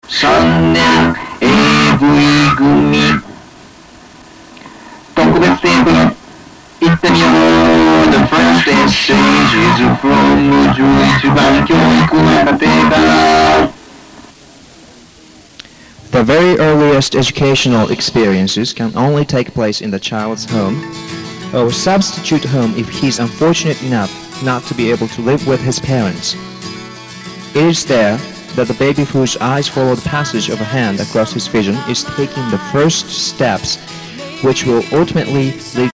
今、聞いて分かるのは、この英語では、5秒に一回ぐらい、声が小さい感じがするんです。
あと、男性なのに、女性っぽいかんじの声のトーンがあり、それが「きもい」かんじなんです。